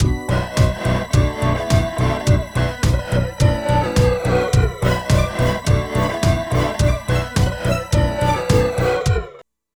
47 LOOP   -R.wav